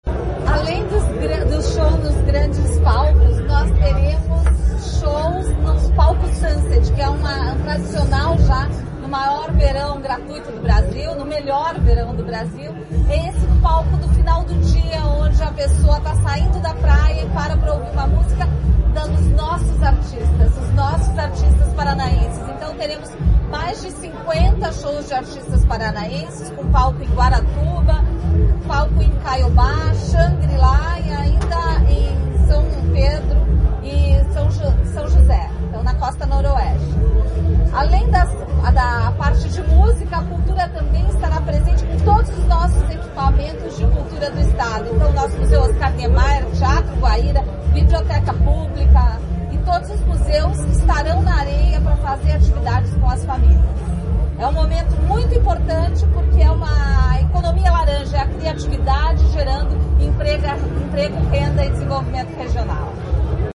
Sonora da secretária da Cultura, Luciana Casagrande Pereira, sobre os palcos Sunset no Verão Maior Paraná